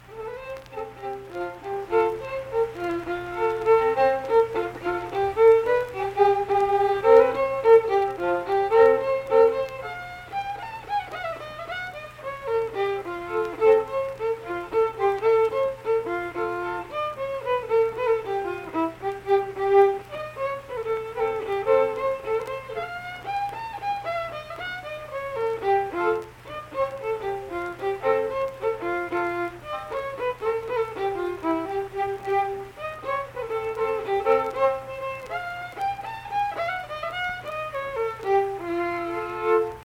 Unaccompanied vocal and fiddle music
Instrumental Music
Fiddle
Saint Marys (W. Va.), Pleasants County (W. Va.)